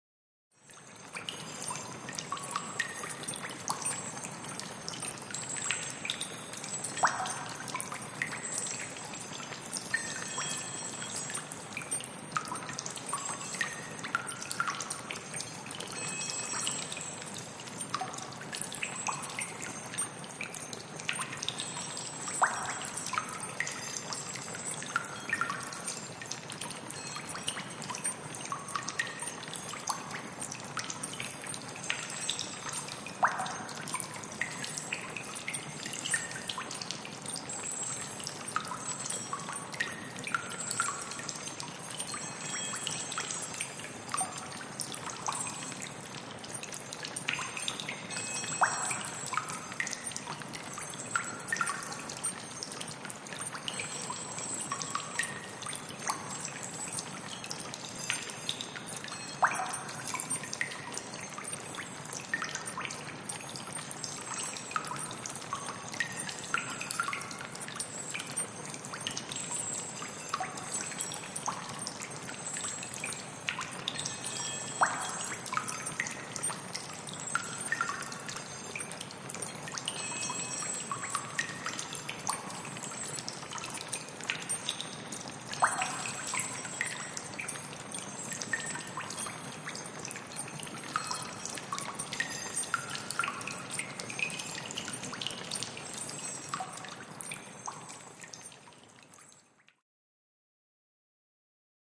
Dungeon Ambiance